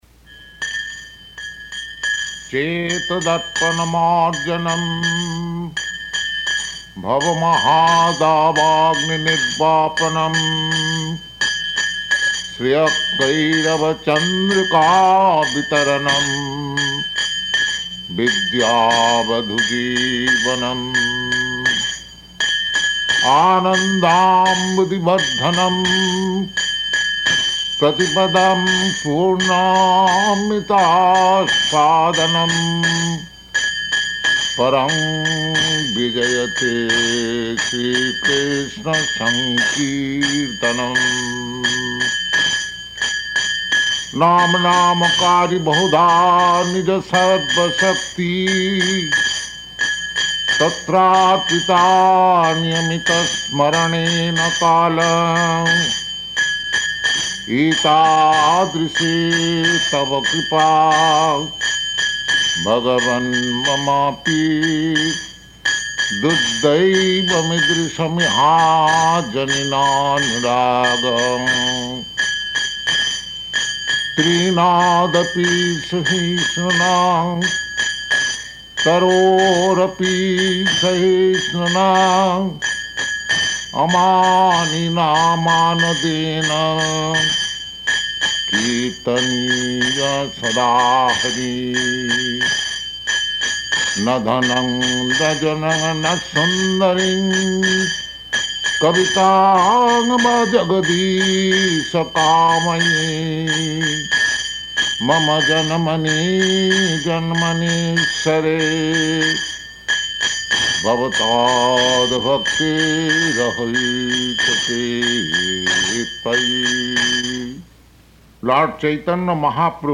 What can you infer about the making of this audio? Purport to Śrī Śrī Śikṣāṣṭakam [partially recorded] Location: Los Angeles